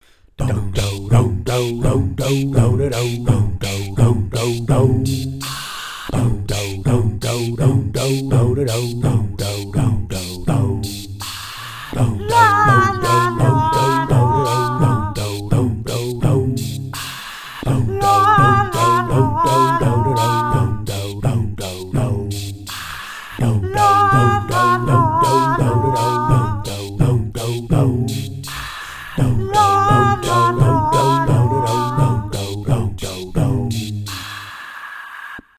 A Silly Vocal Tune